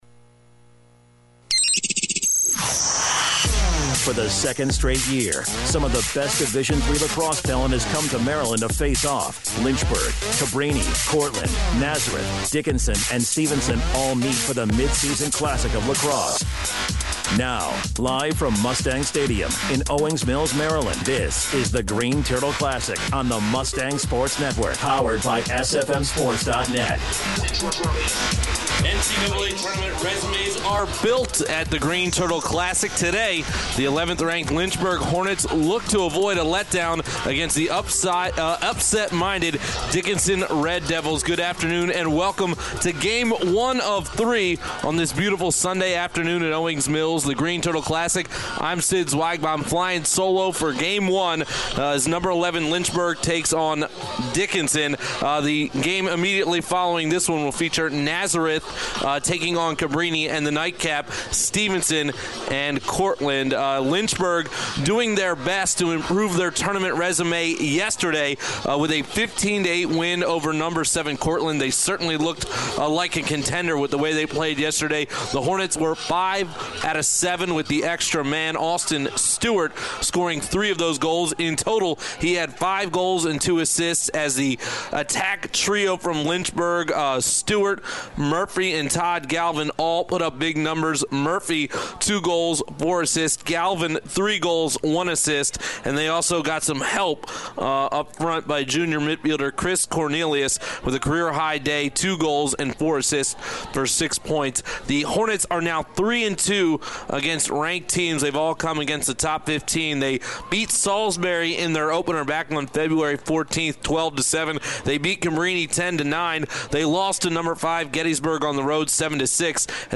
The Hornets sting the Red Dragons 14-9 in day two of the Greene Turtle Classic from Owings Mills, MD.